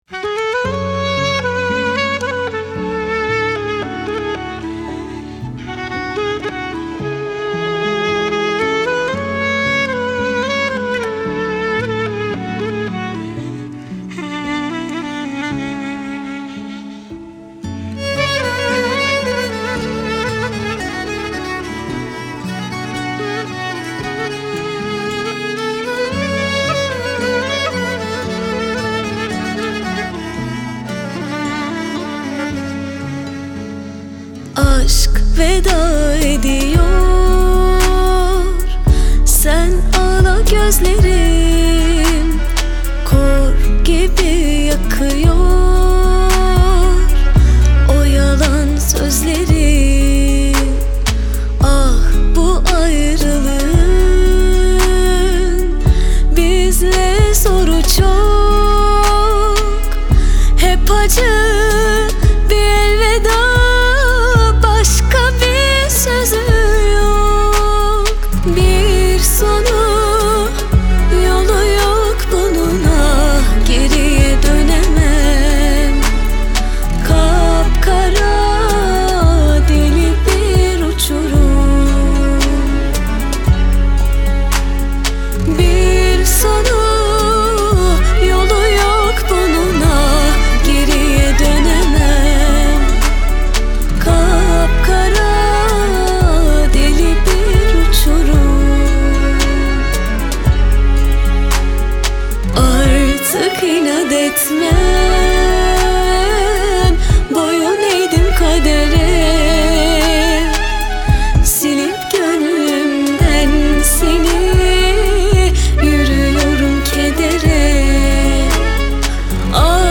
dizi müziği, duygusal hüzünlü rahatlatıcı şarkı.